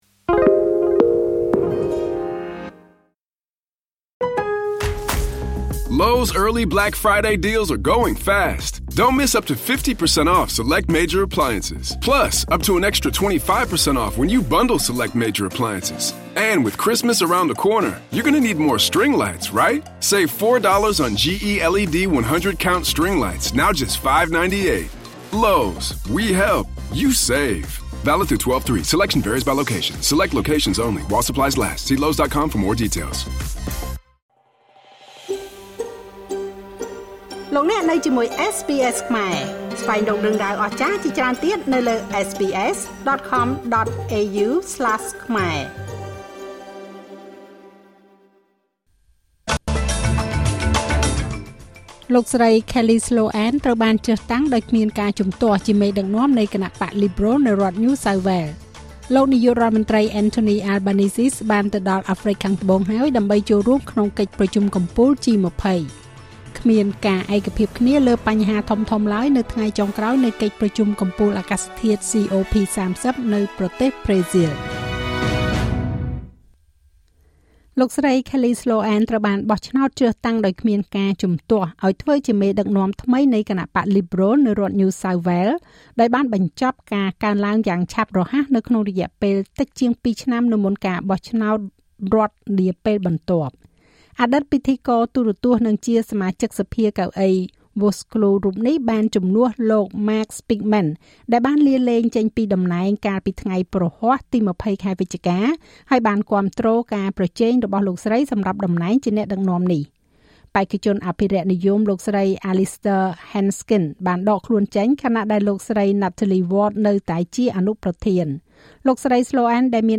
នាទីព័ត៌មានរបស់SBSខ្មែរសម្រាប់ថ្ងៃសុក្រ ទី២១ ខែវិច្ឆិកា ឆ្នាំ២០២៥